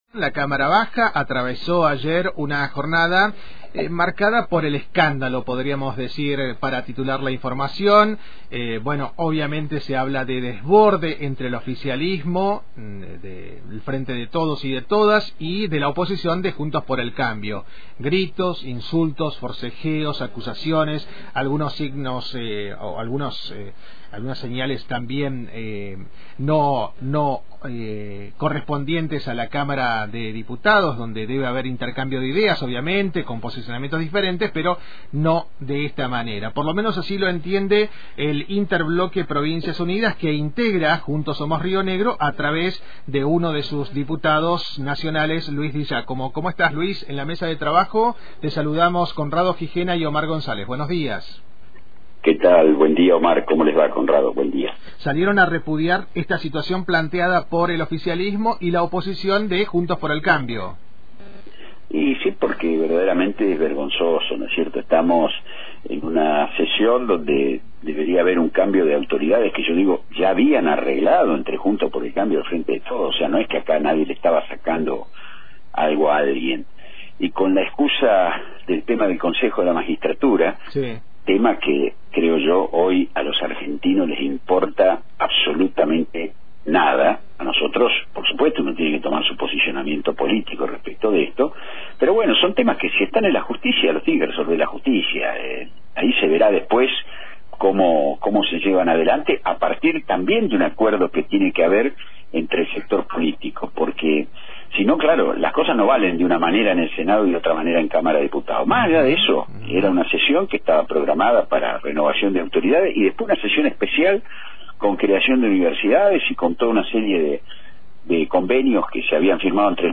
El Diputado Nacional por Juntos por Somos Rio Negro y del interbloque de Provincias Unidas, Luís Di Giácomo, conversa con Antena Libre 89.1 por los desmanes en la Cámara de Diputados del pasado jueves.
Escuchá la entrevista completa a Luís Di Giácomo acá: